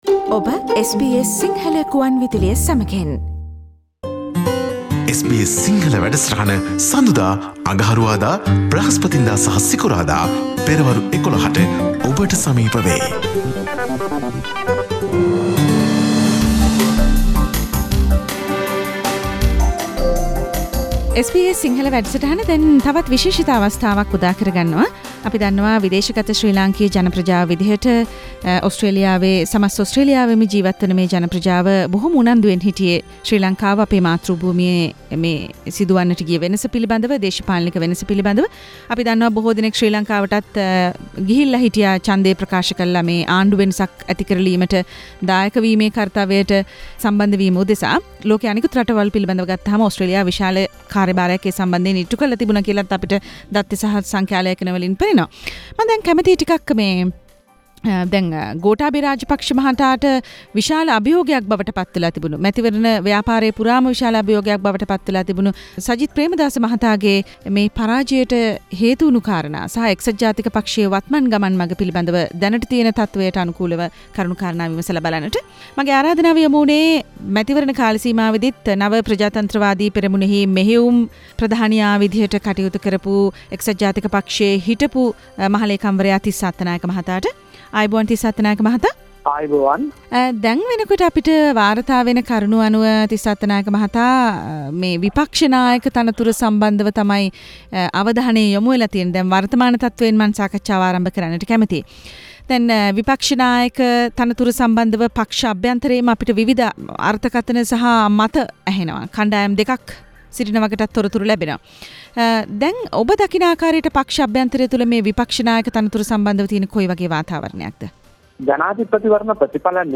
Tissa Attanayake talks to SBS Sinhala radio Source: SBS Sinhala